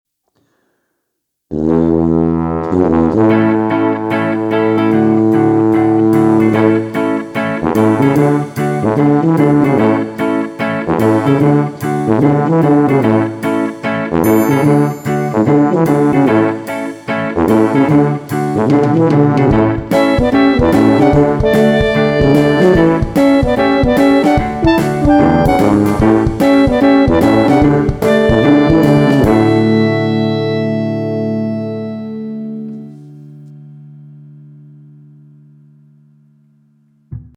10 весело звучит но маловато